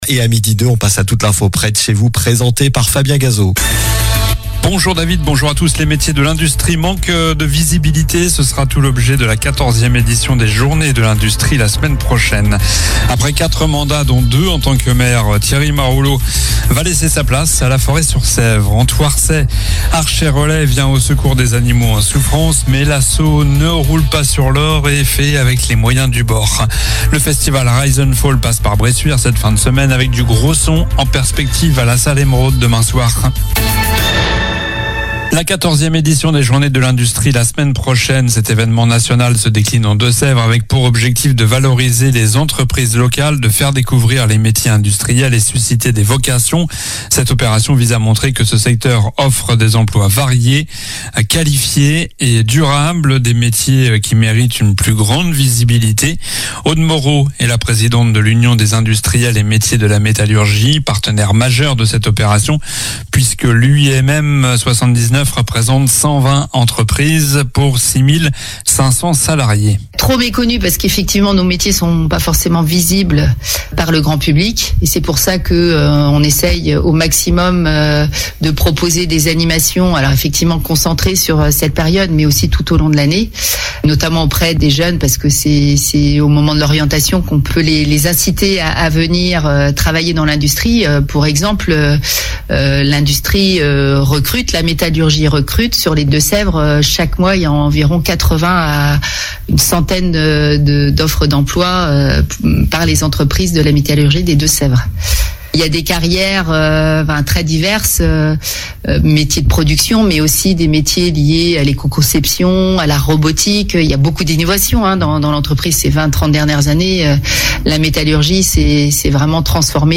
Journal du jeudi 13 novembre (midi)